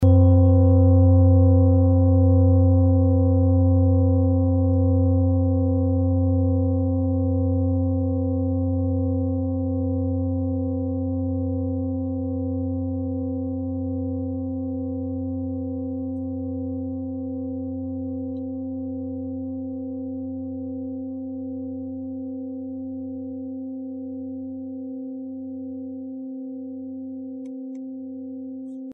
Klangschale Nepal Nr.41
Klangschale-Gewicht: 1520g
Klangschale-Durchmesser: 25,9cm
(Ermittelt mit dem Filzklöppel)
klangschale-nepal-41.mp3